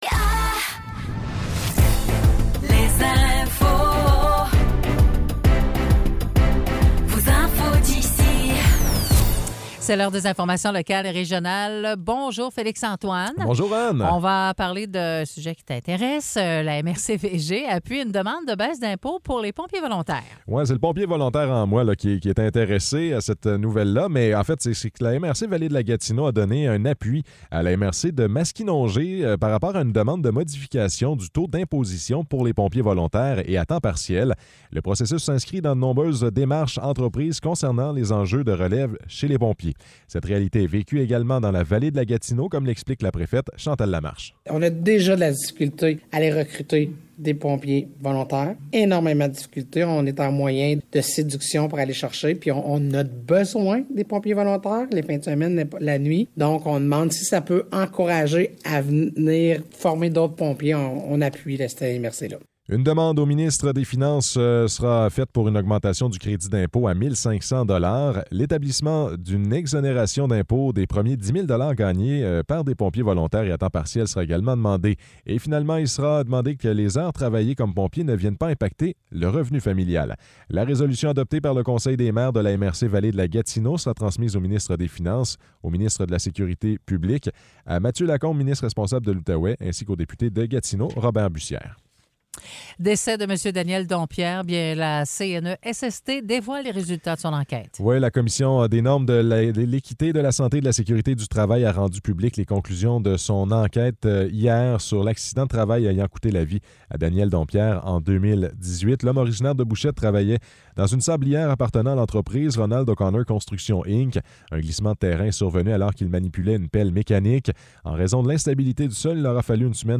Nouvelles locales - 19 octobre 2023 - 10 h